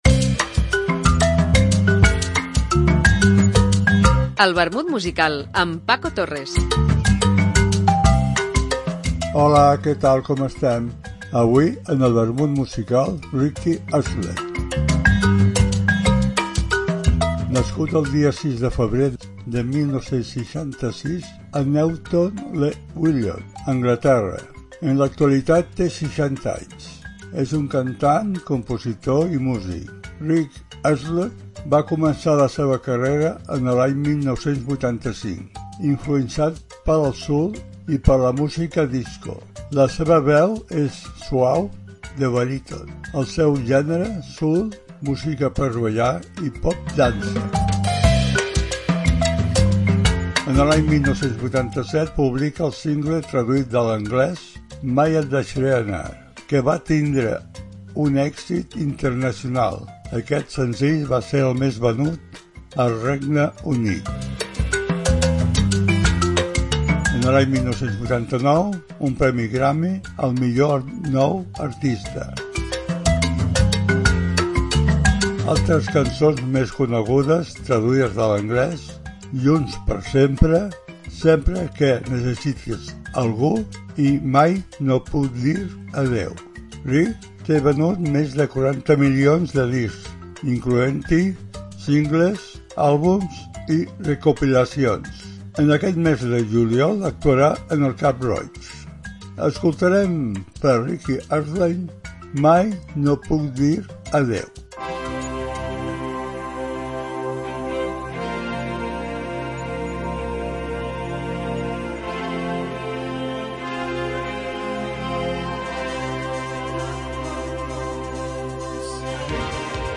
Una apunts biogràfics acompanyats per una cançó.